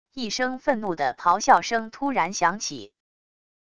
一声愤怒的咆哮声突然响起wav音频